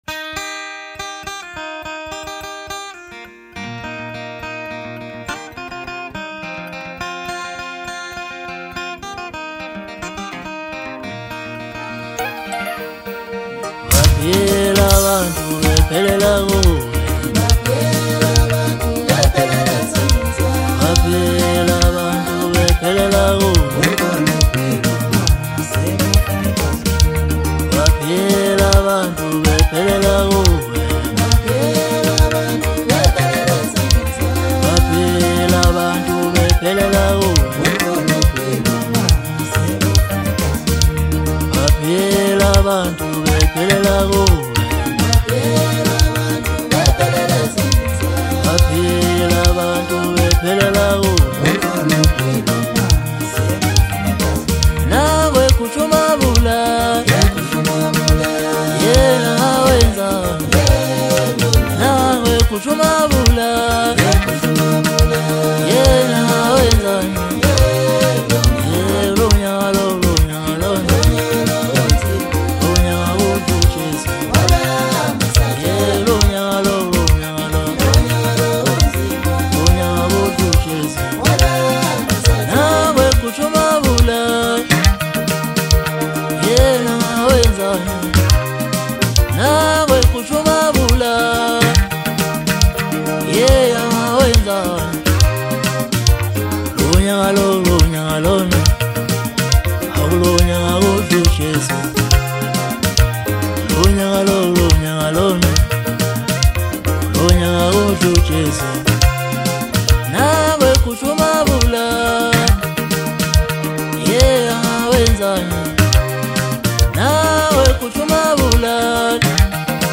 Zulu Maskandi